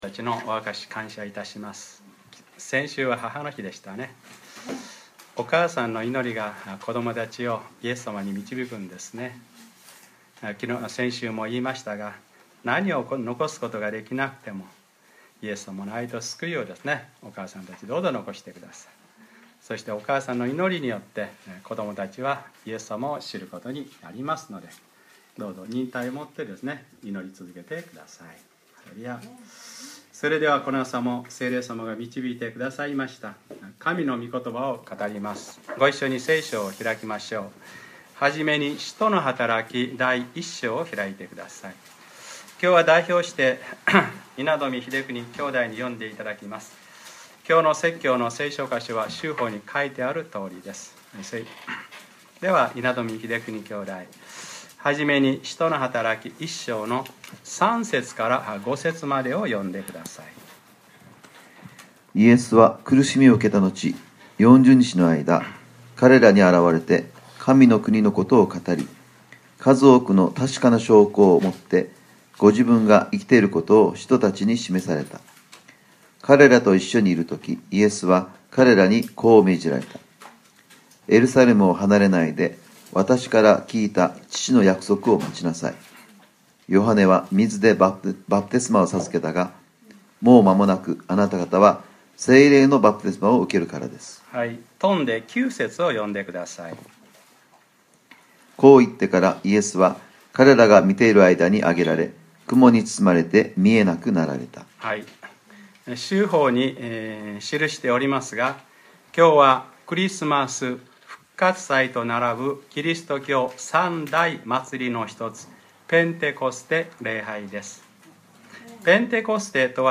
2013年5月19日(日）礼拝説教 『ペンテコステ礼拝：聖霊の人格と働きについて』